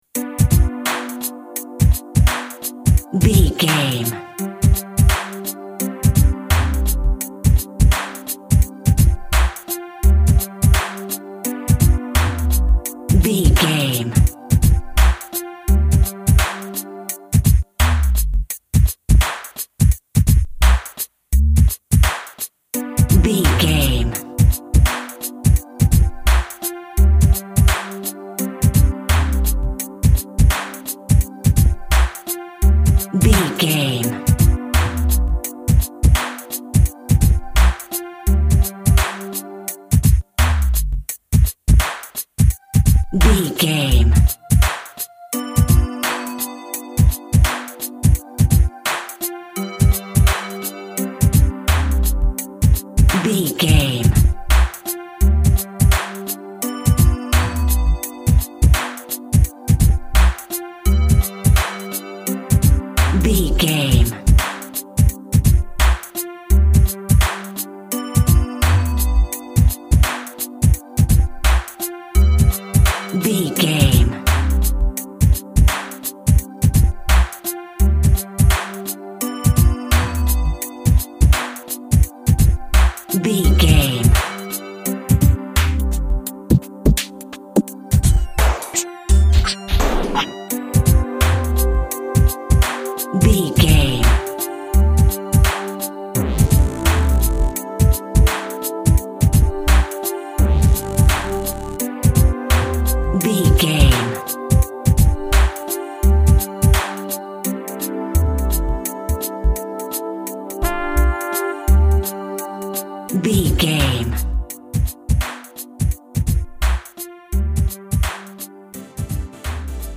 Hip Hop for Business.
Aeolian/Minor
synth lead
synth bass
hip hop synths